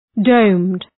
Shkrimi fonetik {dəʋmd}